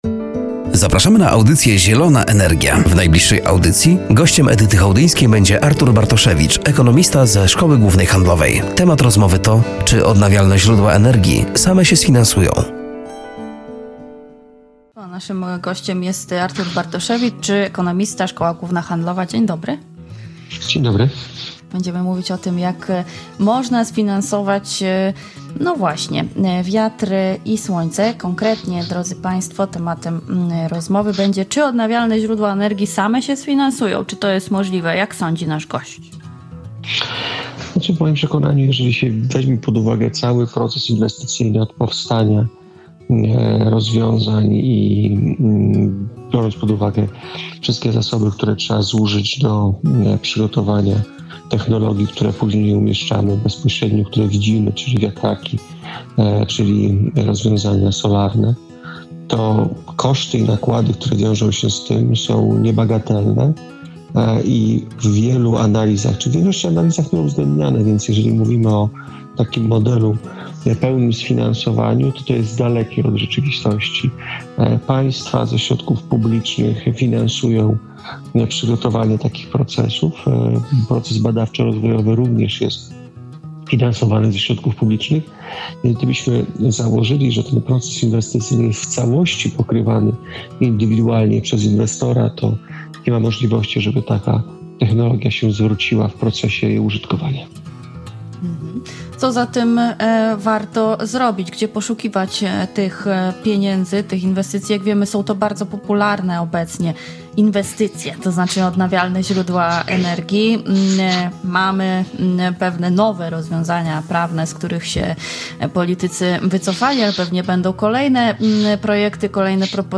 Z rozmowy dowiemy się czy odnawialne źródła energii same się sfinansują?
“Zielona Energia” w piątek o g. 10.15 na antenie Radia Nadzieja.